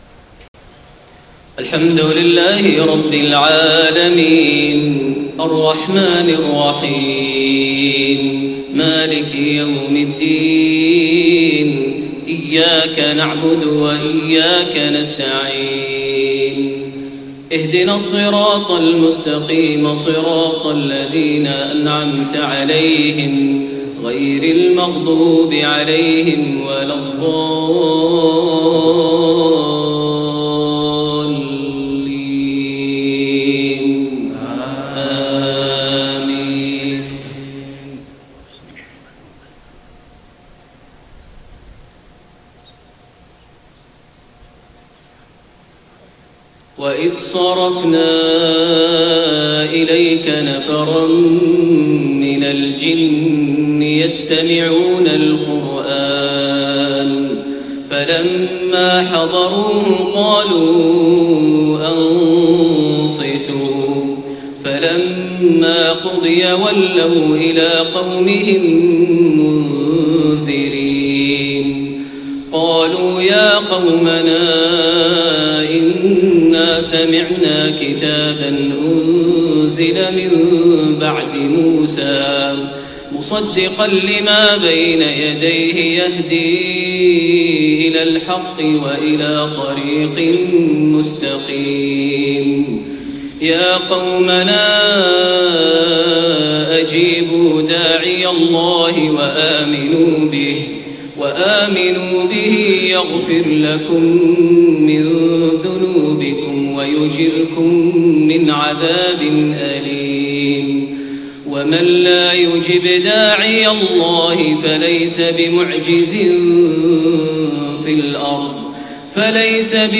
صلاة المغرب1-4-1432 من سورة الأحقاف29-35 > 1432 هـ > الفروض - تلاوات ماهر المعيقلي